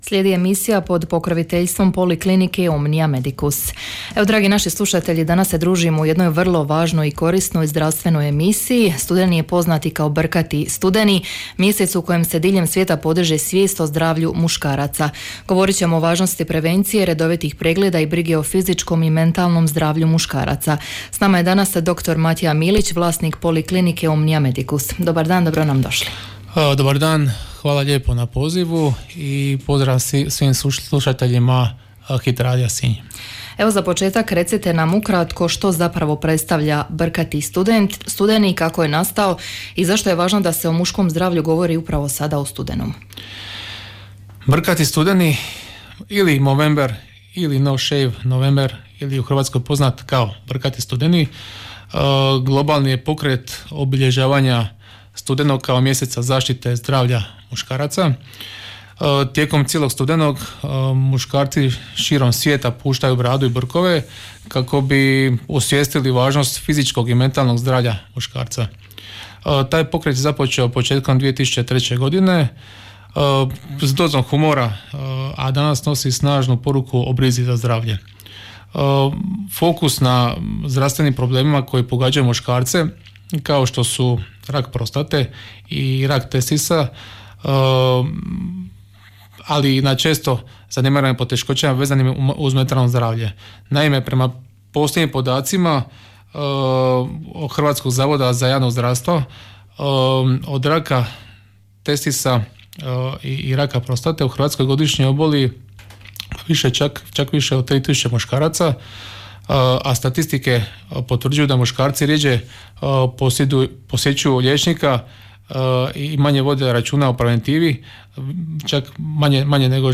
Danas je u jutarnjem programu Hit radija emitirana emisija pod pokroviteljstvom Poliklinike Omnia Medicus iz Sinja.